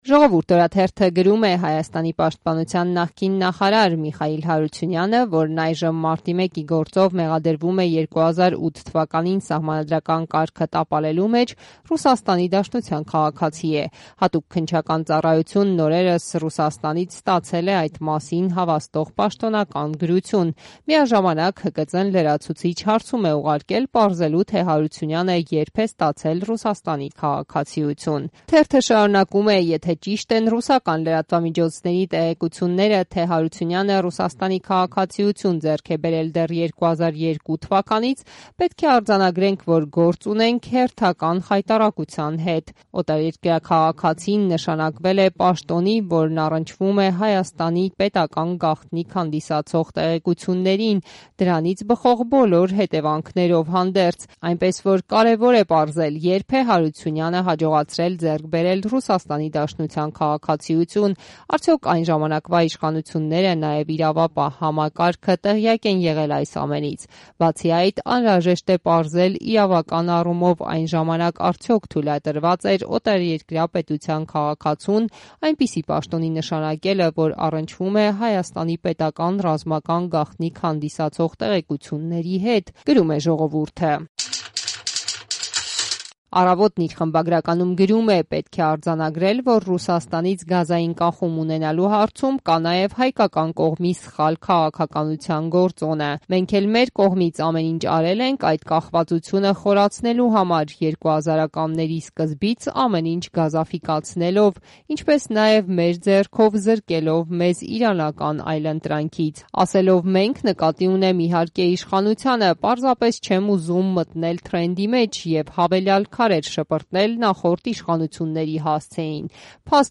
Մամուլի տեսություն